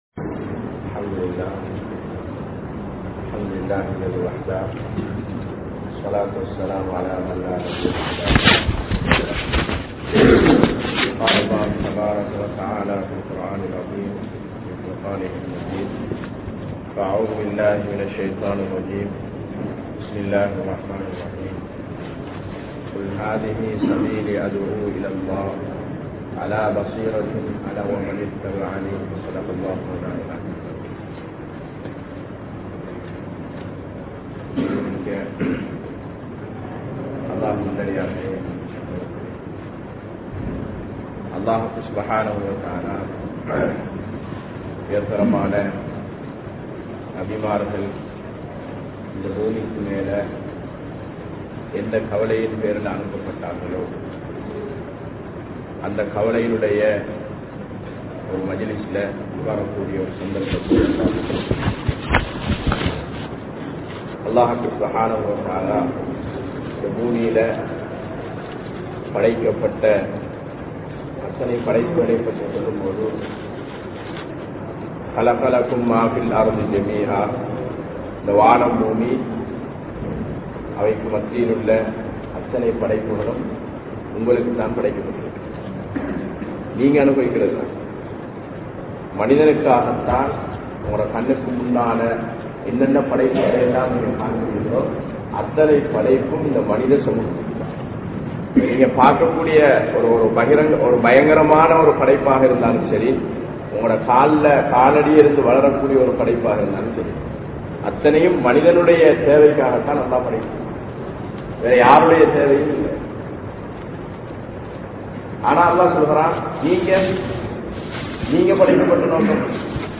Eemaanukkaana Ulaippu (ஈமானுக்கான உழைப்பு) | Audio Bayans | All Ceylon Muslim Youth Community | Addalaichenai
Grand Jumua Masjidh(Markaz)